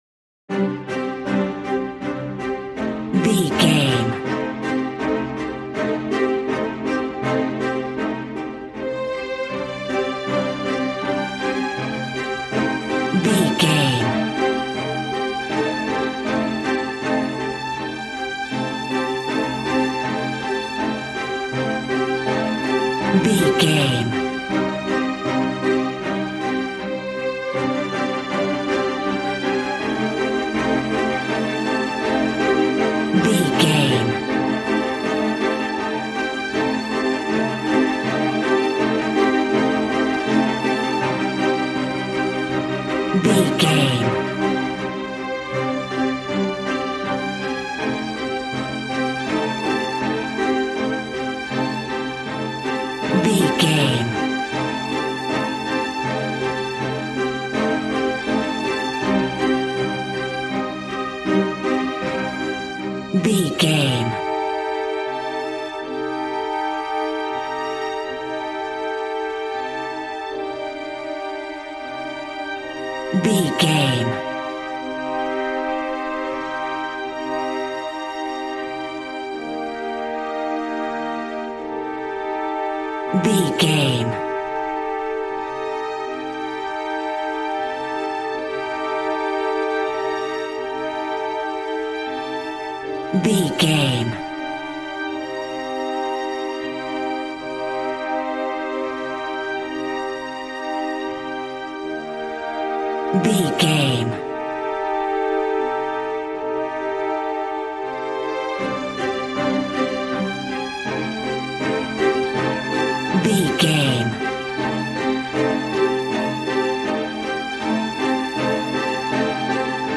Aeolian/Minor
regal
cello
violin
strings